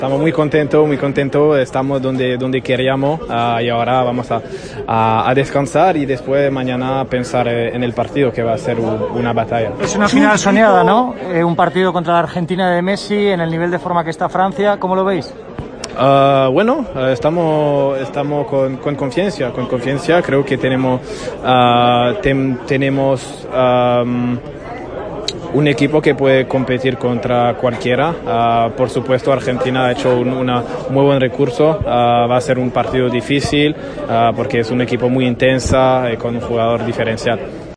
"Estamos muy contentos, donde queríamos, y ahora vamos a descansar. Mañana pensaremos en la final, que va a ser una batalla", explicó el defensa del Barcelona al micrófono de la Cadena COPE.